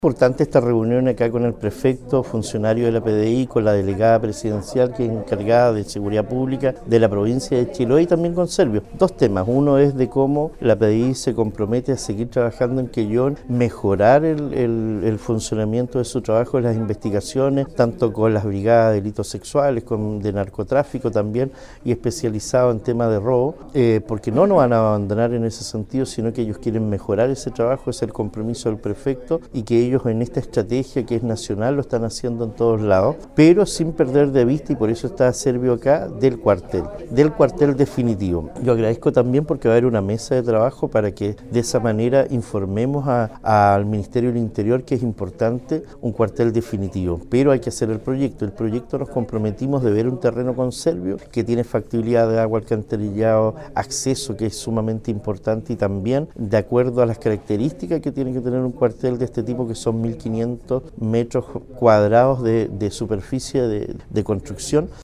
Para el alcalde de Quellón, Cristian Ojeda Chiguay, fue muy importante esta reunión, señalando que ya están trabajando en la habilitación de un terreno para la construcción de un lugar que reuna todas las condiciones para que la PDI queda desarrollar su labor policial: